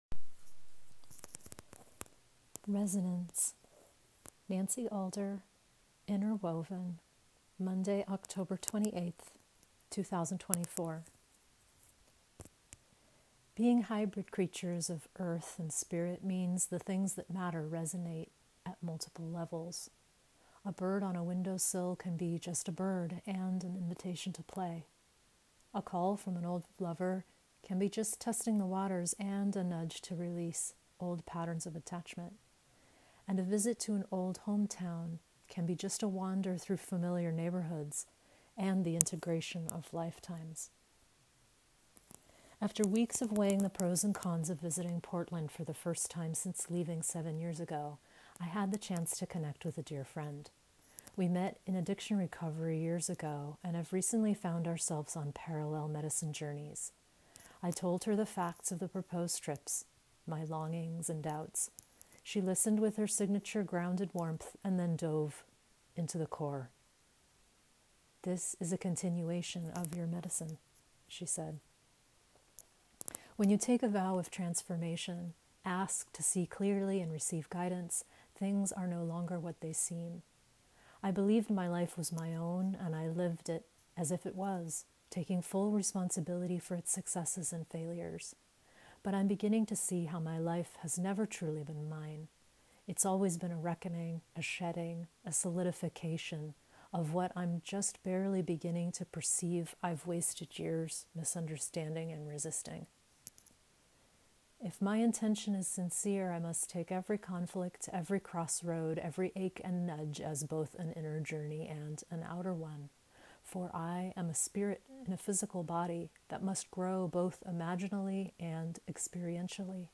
Enjoy this special 13-minute story or let me read it to you via the link at the top of the webpage